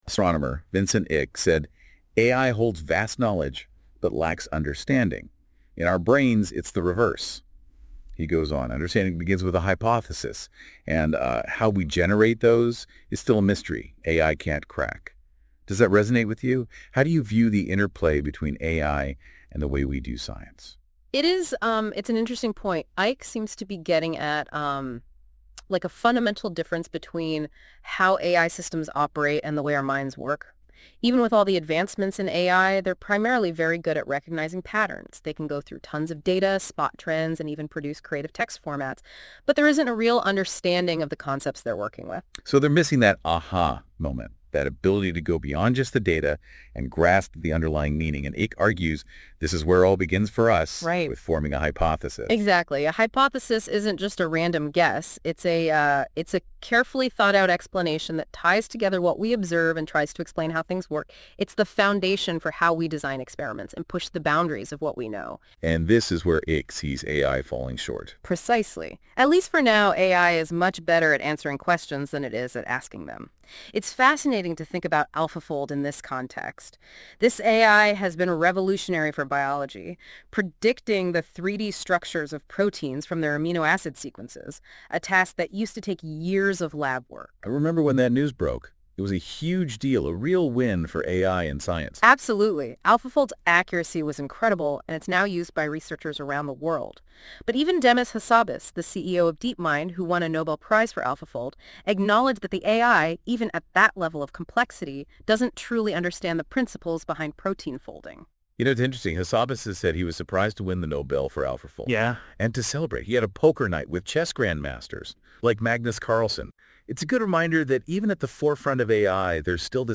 BTW - This is a podcast in an interview style (much more dynamic) and much more engaging! read more